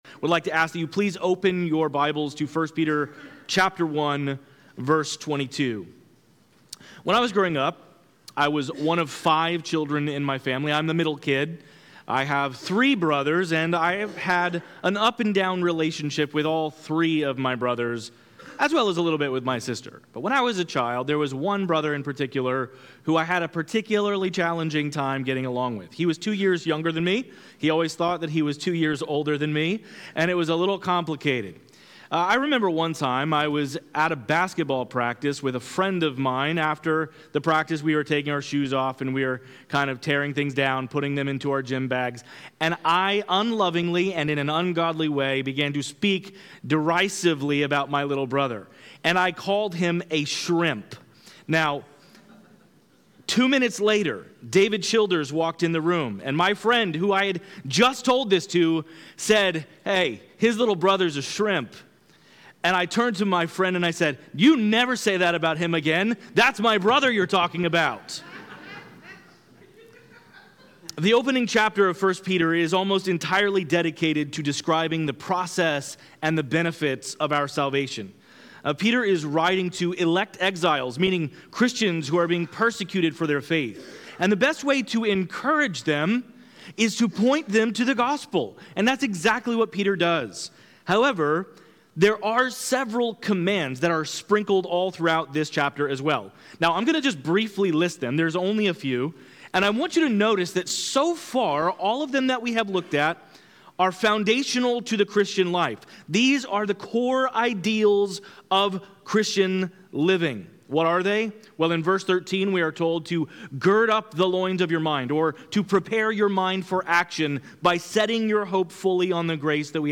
This podcast contains the sermons preached at Levittown Baptist Church in Levittown, New York.